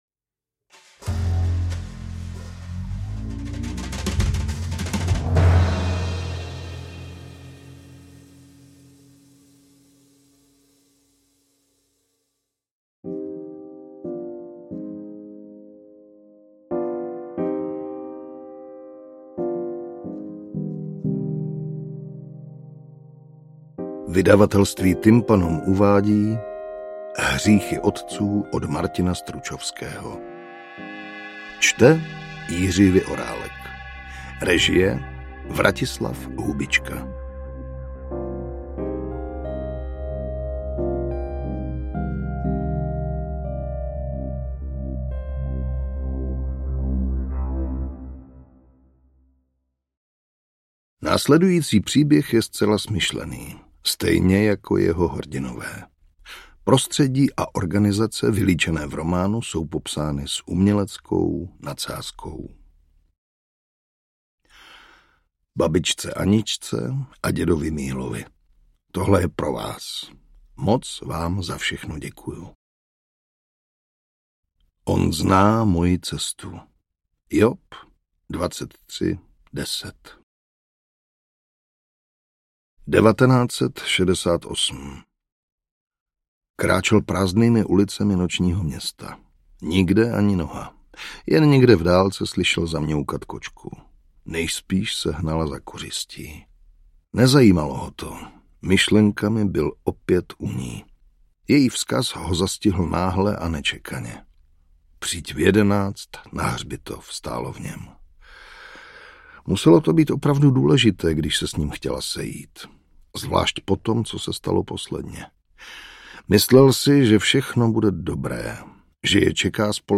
Interpret:  Jiří Vyorálek
detektivky
AudioKniha ke stažení, 20 x mp3, délka 8 hod. 11 min., velikost 450,5 MB, česky